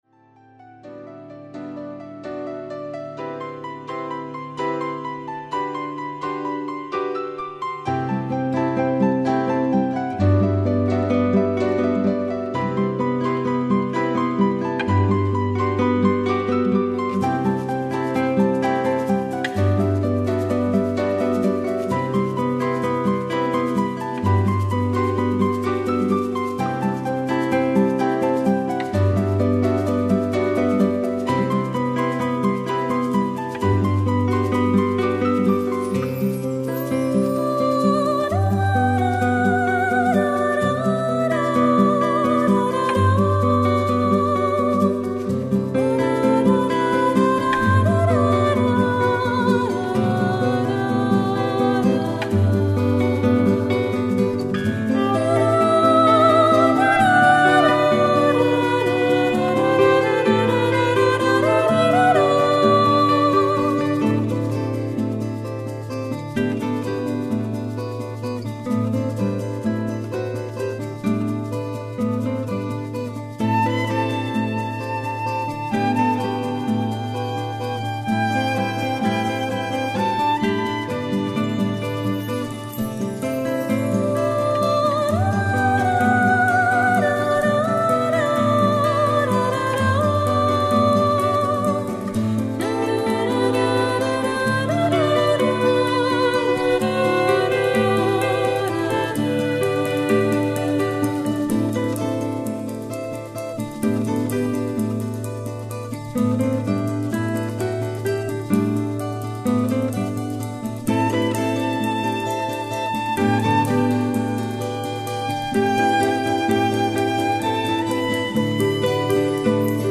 guitarra española